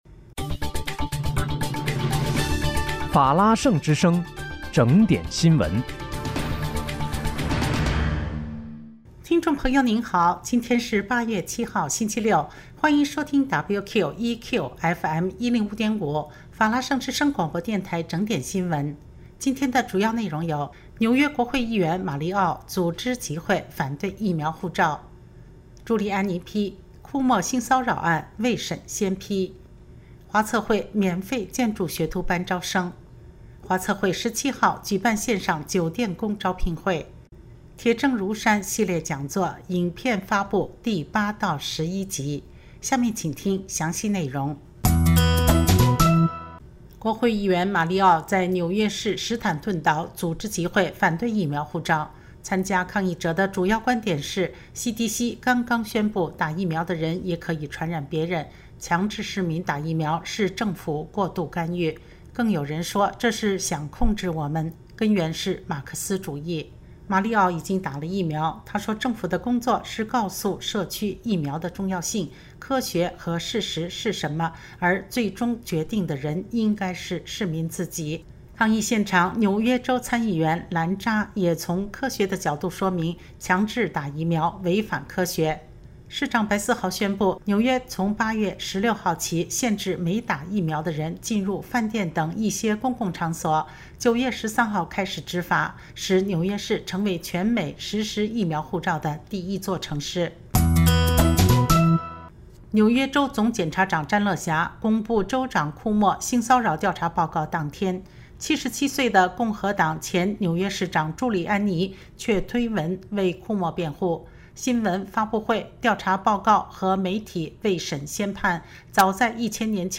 8月7日（星期六）纽约整点新闻